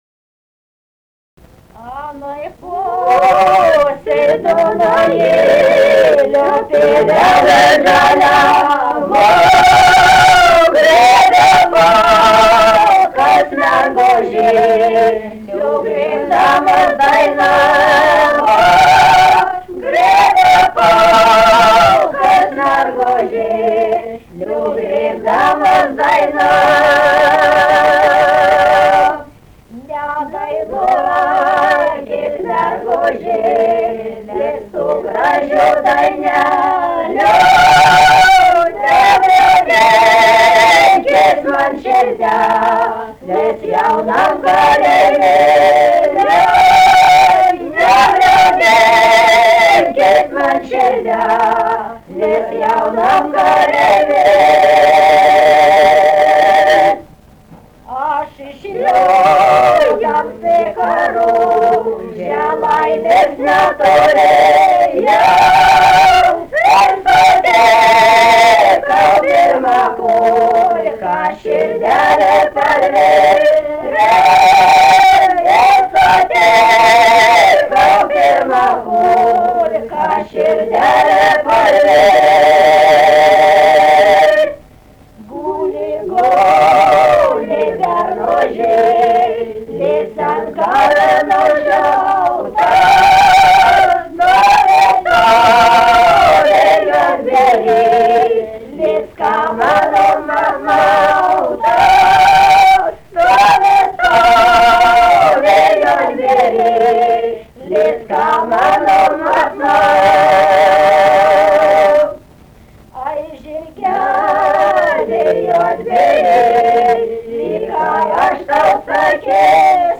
daina, karinė-istorinė
Jukoniai
vokalinis